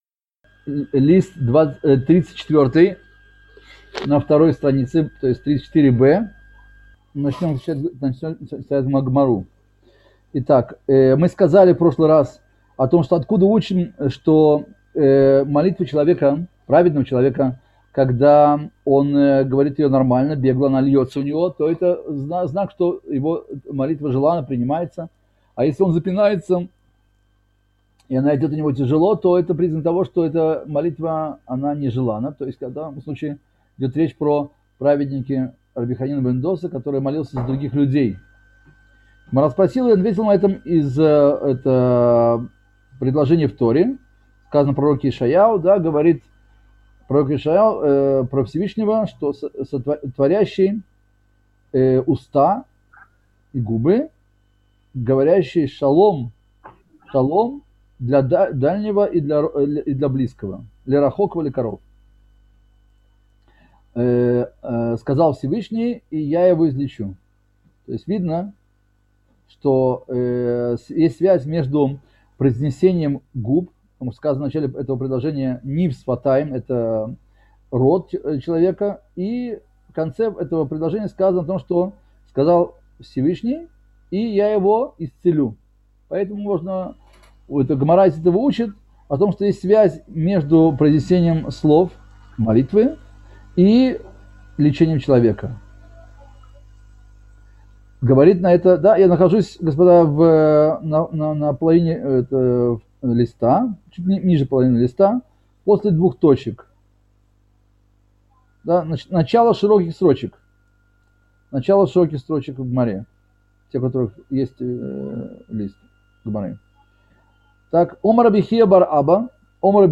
Цикл уроков по изучению мишны Брахот